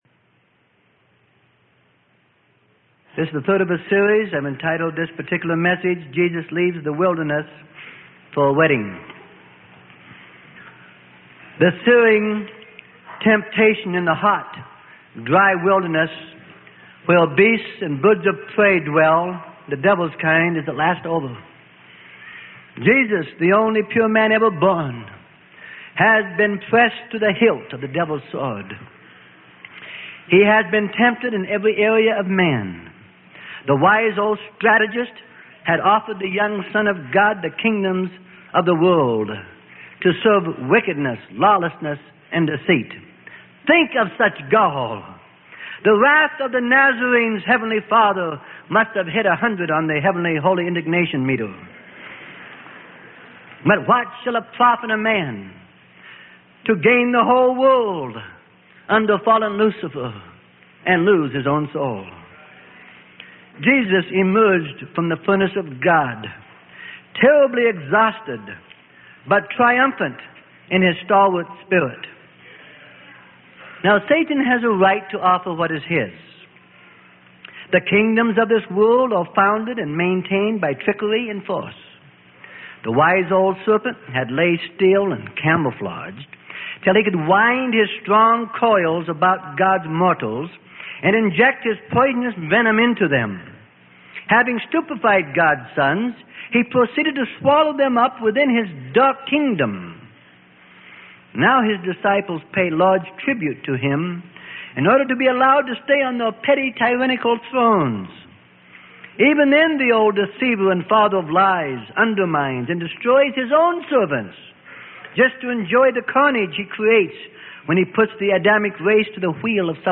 Sermon: The Life Of Jesus - Part 03 Of 33.